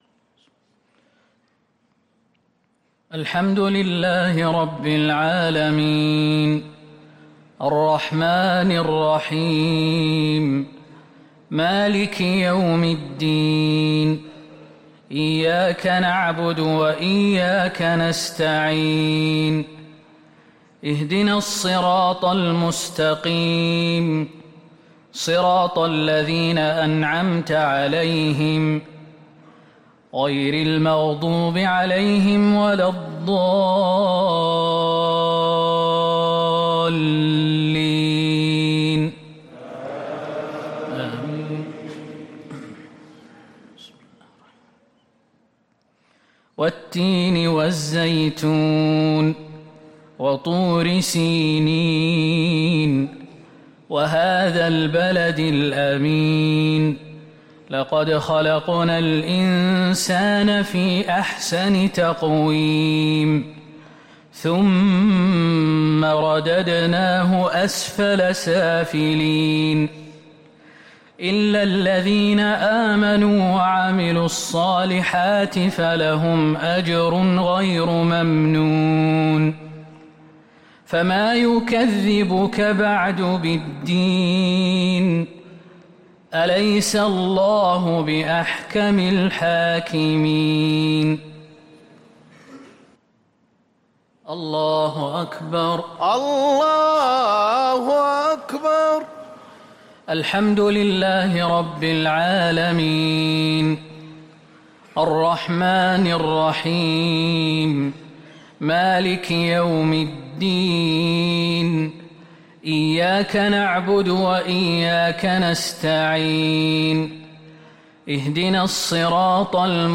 عشاء الأثنين 9-6-1444هـ سورتي التين و العصر | Isha prayer Surah At-Tin and Al-A’asr 2-1-2023 > 1444 🕌 > الفروض - تلاوات الحرمين